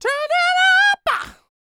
DD FALSET058.wav